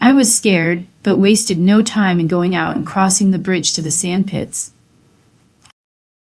Text-to-Speech
more clones